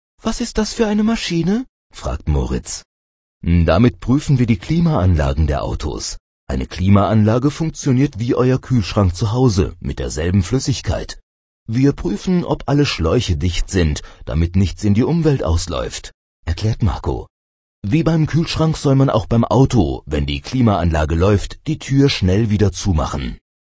Das Hörbuch "Unsere Autowerkstatt" für unsere kleinen Kunden
Hörbuch Seite 4